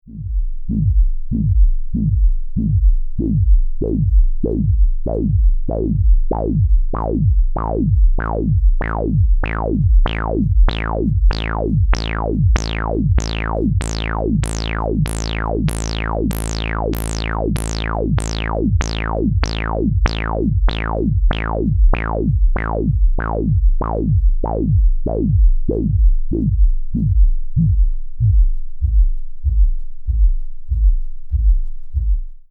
Example sounds, no effects used, input signal is static saw wave from function generator:
filter sweep ramp down, high Q
ldr-filtersweep-rampdown-saw55hz-highq.mp3